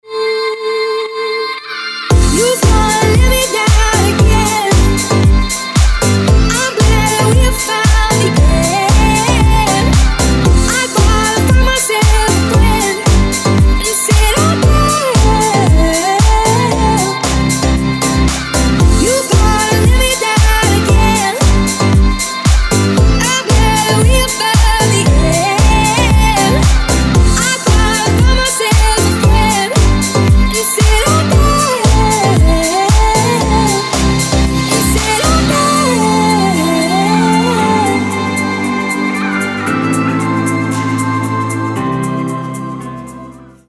• Качество: 256, Stereo
женский вокал
dance
club
house
Vocal House